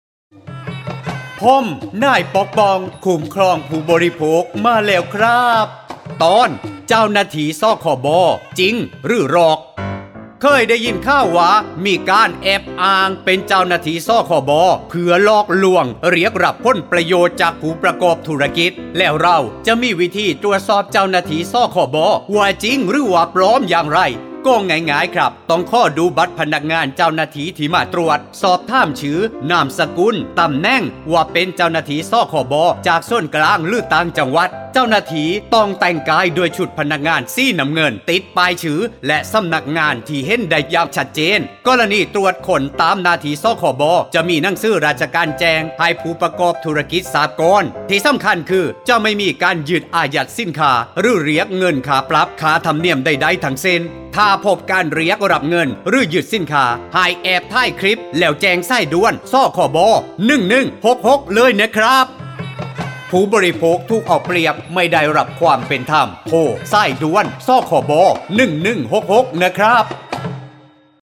สื่อประชาสัมพันธ์ MP3สปอตวิทยุ ภาคใต้
105.สปอตวิทยุ สคบ._ภาคใต้_เรื่องที่ 15_.mp3